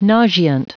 Prononciation du mot nauseant en anglais (fichier audio)
Prononciation du mot : nauseant
nauseant.wav